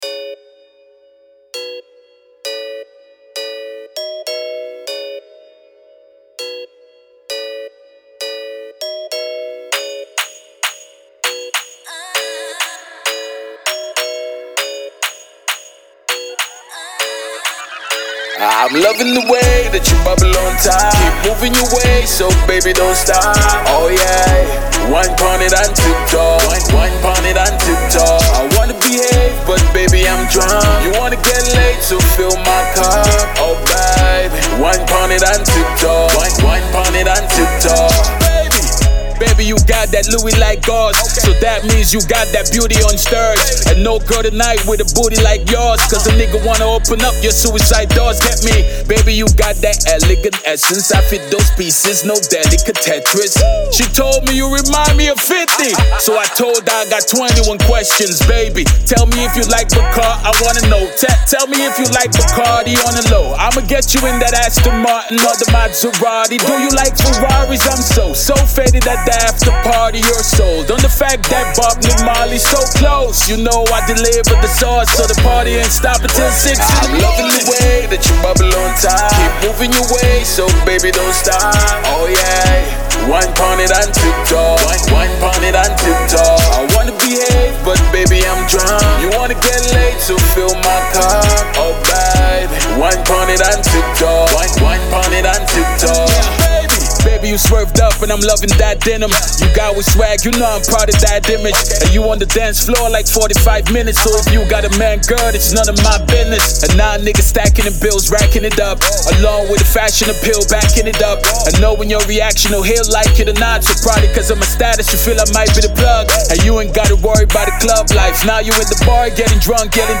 American rapper
a very infectious mind-blowing Jam